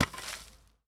Bat Throw.wav